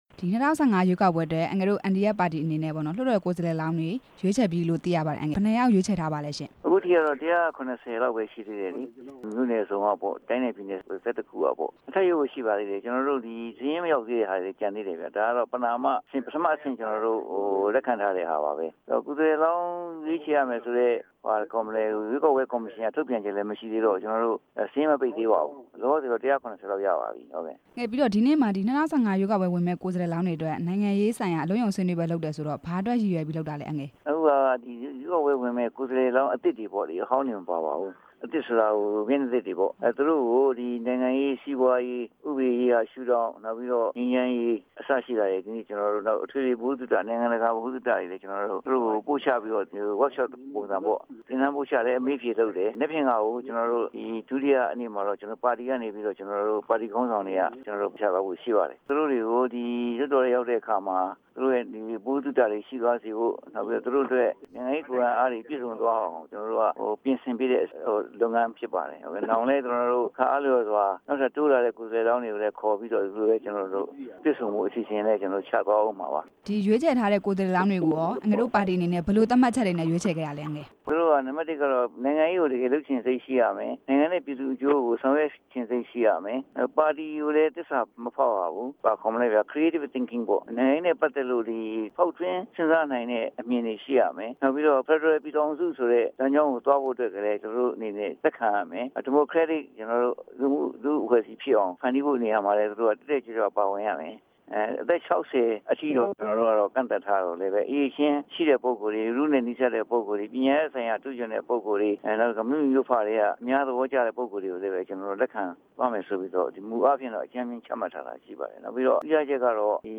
မေးမြန်းထားပါတယ်။